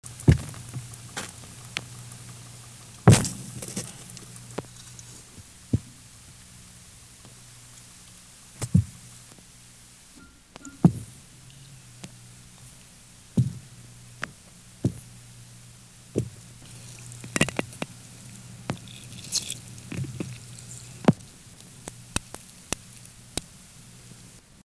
Type of sound produced escape knocks, toothy clicks, thumps
Sound production organ teeth & swimbladder
Sound mechanism vibration, stridulation
Behavioural context under duress
Remark 2 specimens under mild duress (electrical stimulation)